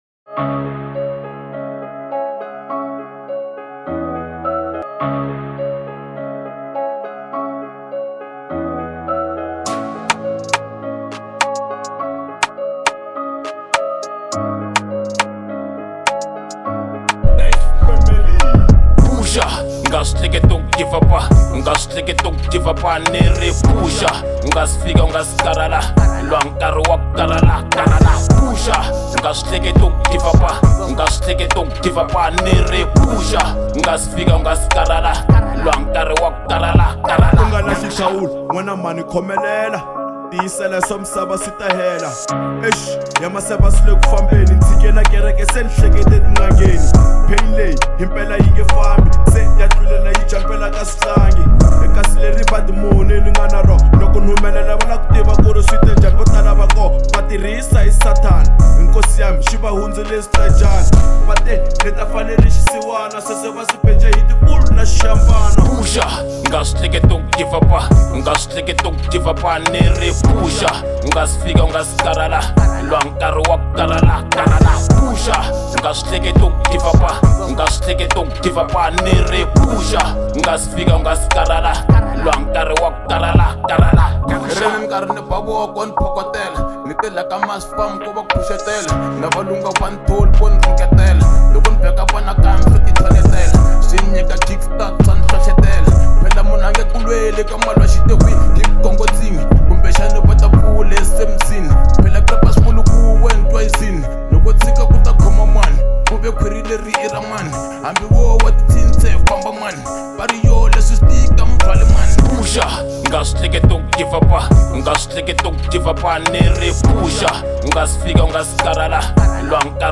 03:27 Genre : Hip Hop Size